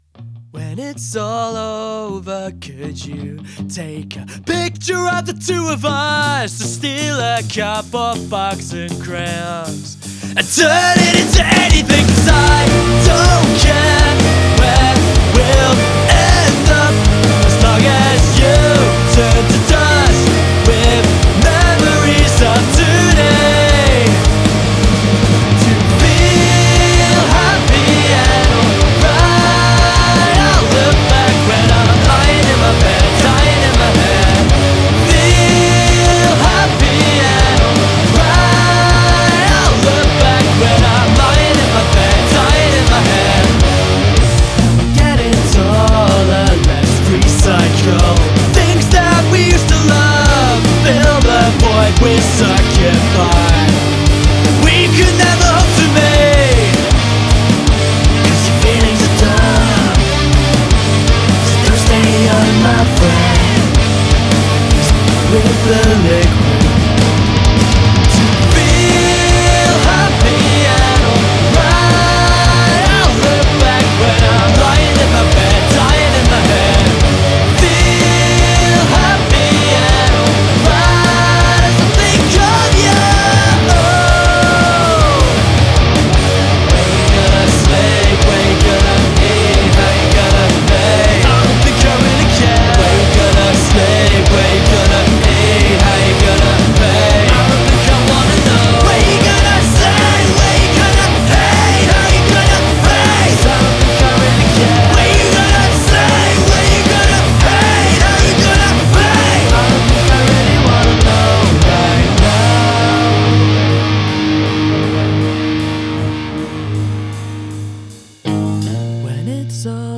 underground Alt. Rock